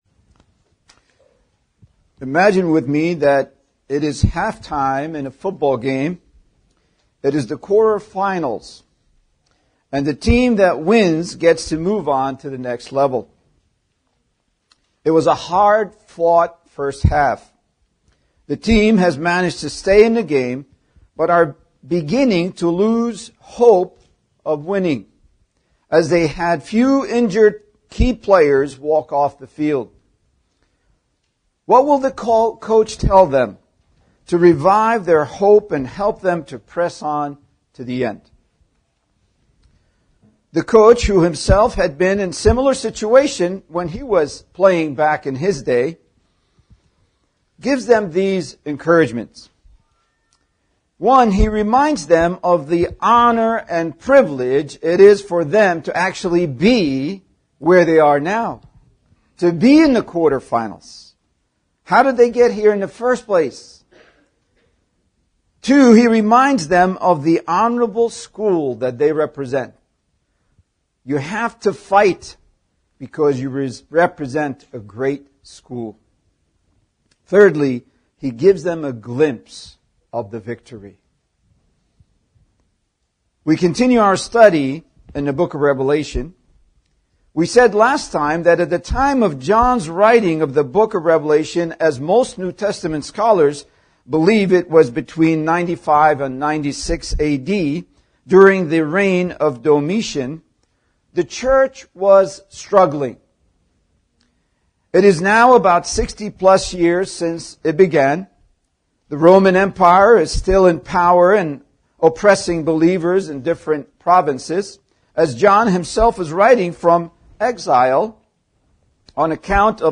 Comfort for Suffering Saints | SermonAudio Broadcaster is Live View the Live Stream Share this sermon Disabled by adblocker Copy URL Copied!